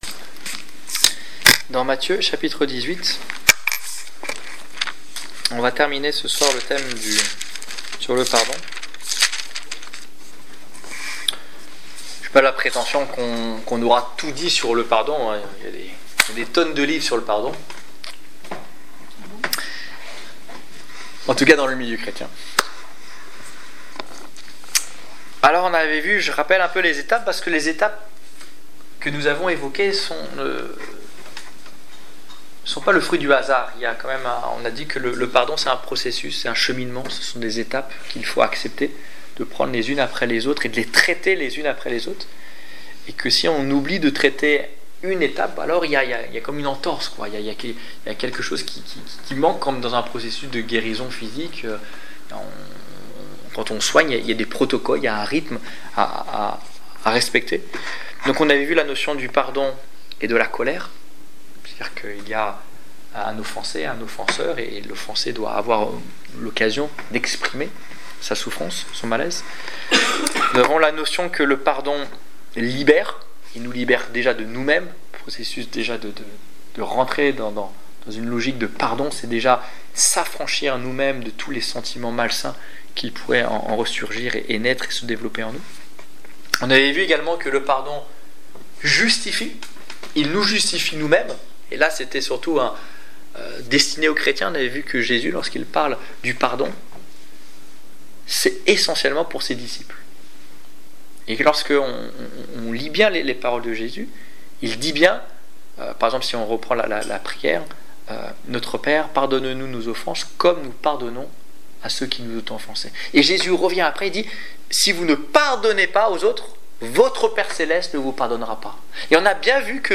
Le pardon (6) - Ses dimensions Détails Prédications - liste complète Annonce de l'évangile du 23 décembre 2016 Ecoutez l'enregistrement de ce message à l'aide du lecteur